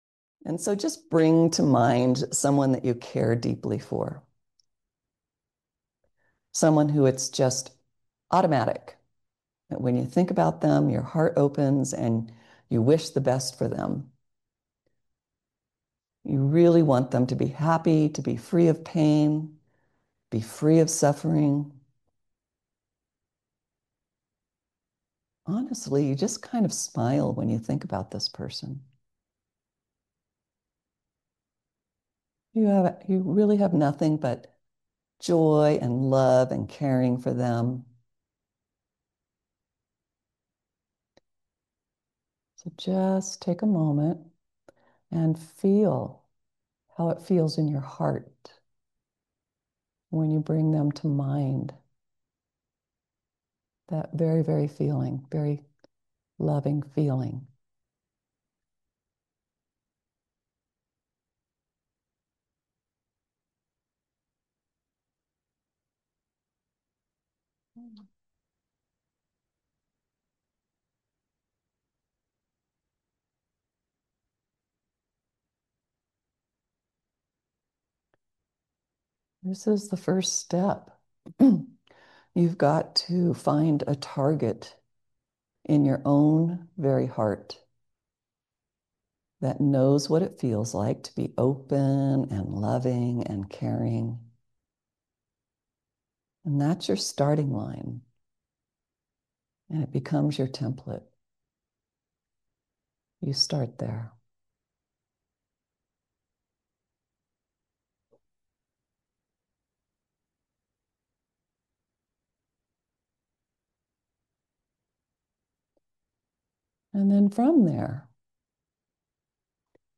Meditation Session
Ghatika Monthly is an in-person and virtual gathering held on the 2nd Saturday of every month, in which CCR expert teachers offer accessible guidance in foundational yet transformative contemplative practices. Ghatika is the Sanskrit term for one 24-minute meditation session, which is the recommended length for beginning meditators.